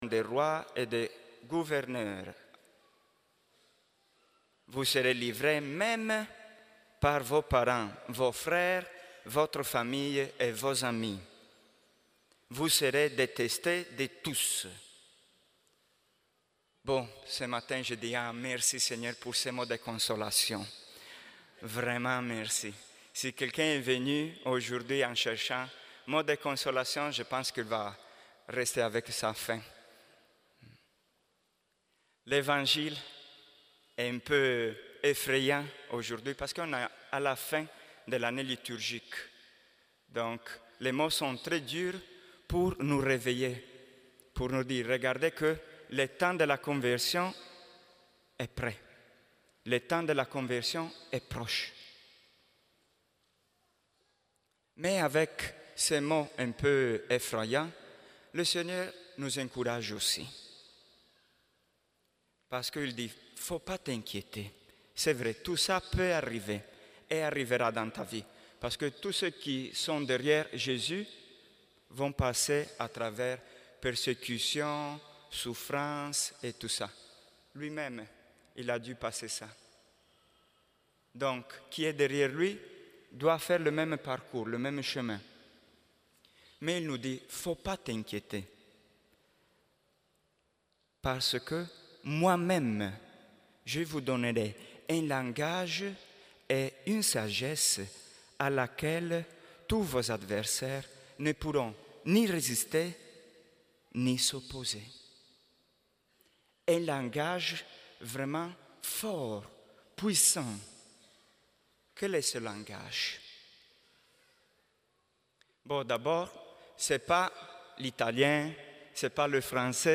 Homélie du Dimanche 17 novembre 2019